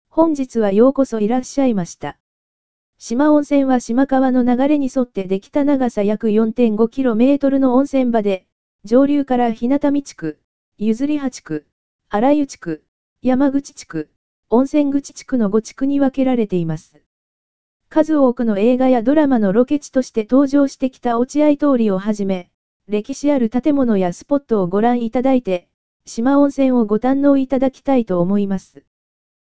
四万温泉協会 – 四万温泉音声ガイド（四万温泉協会）